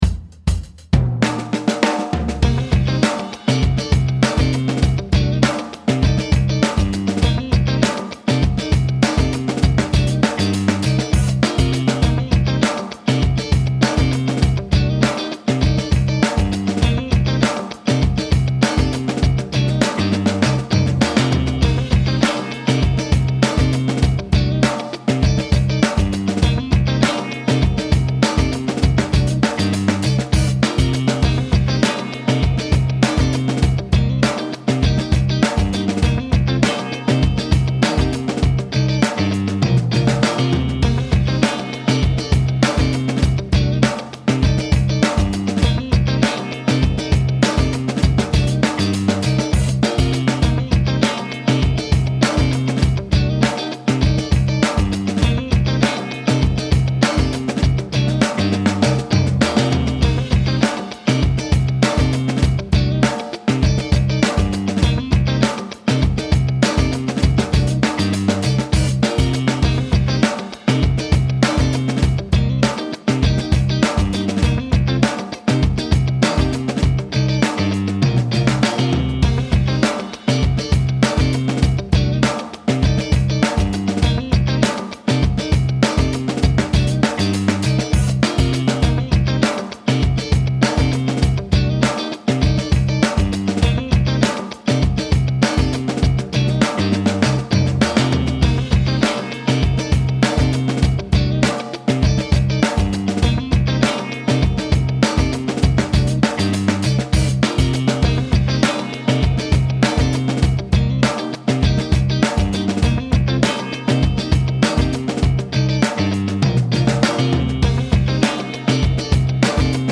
Now it’s time to learn a C Dorian solo that features a number of sliding arpeggio licks.